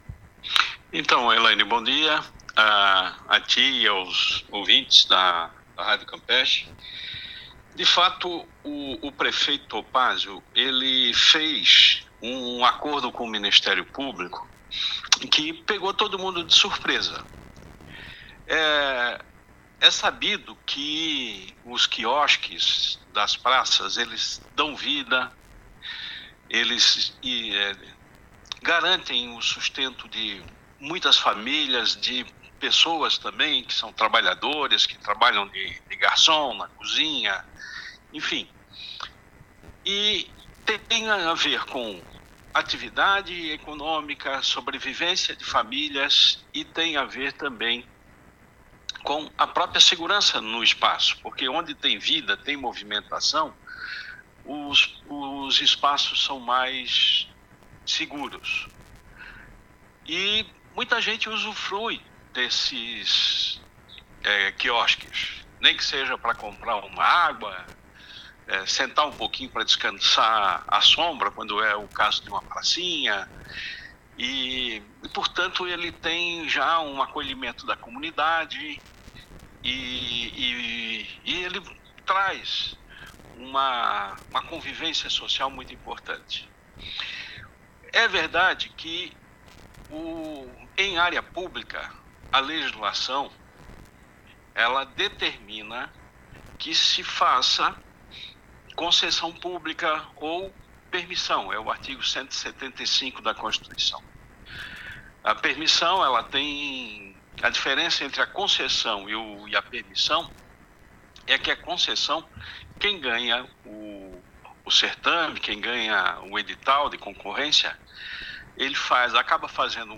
Vereador Afrânio (Psol) fala sobre a derrubada dos quiosques